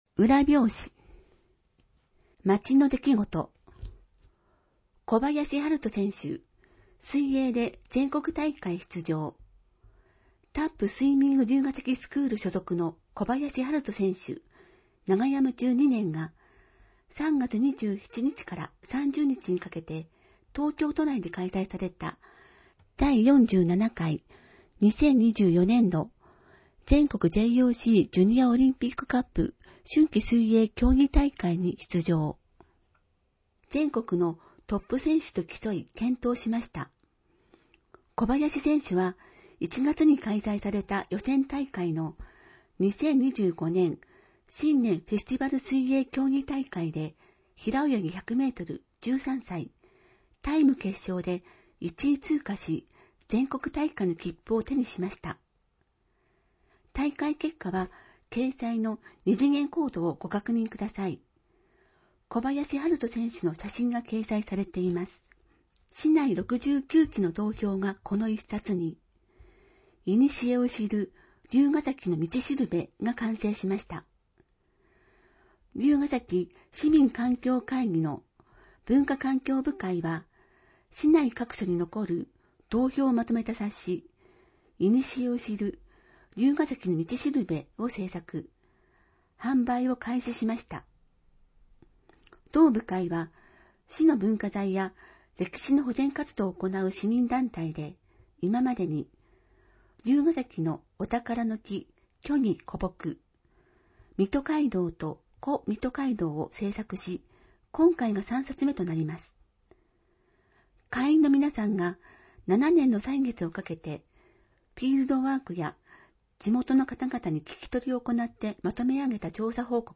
※MP3データは「龍ケ崎朗読の会」のご協力により作成しています。